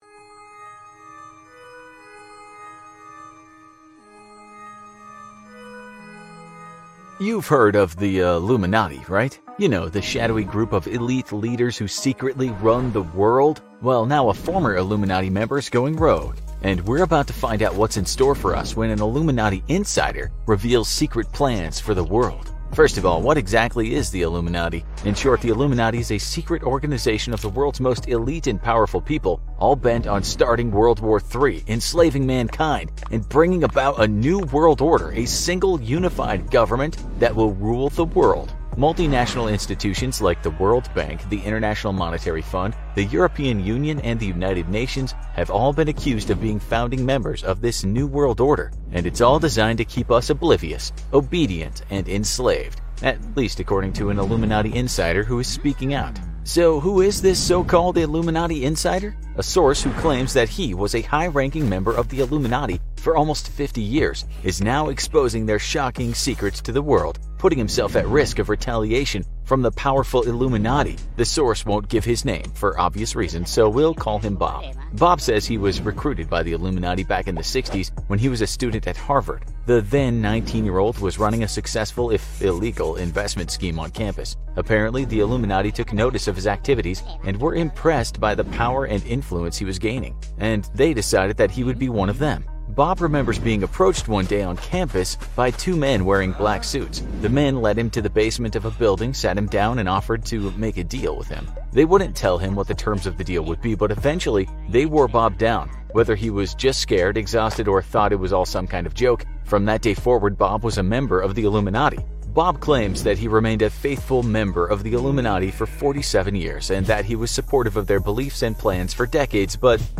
בסרטון המדהים החדש שלנו אנו שומעים מעריק אילומינטי בחיים האמיתיים, והם חושפים כל מיני סודות אסורים, במיוחד על התוכניות של האילומינטי לסדר עולמי חדש!